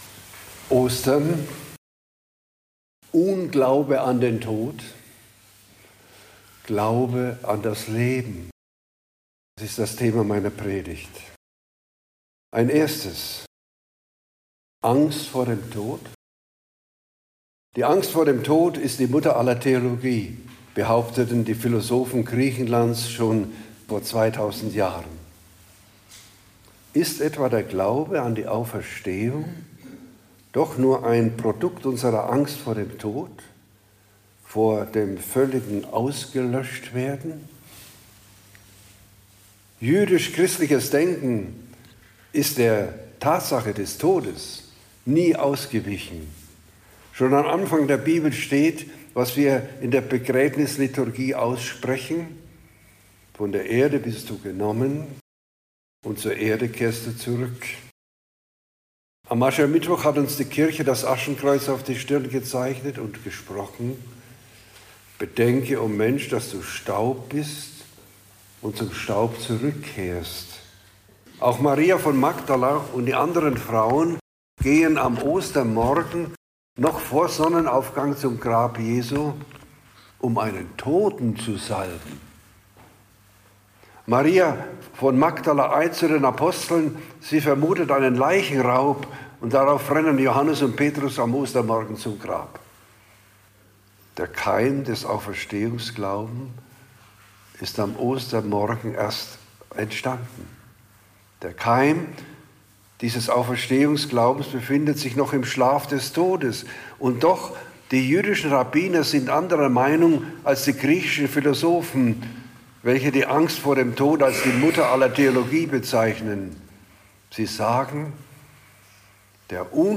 Predigt am Ostersonntag in ULF vom Rosenkranz inHonings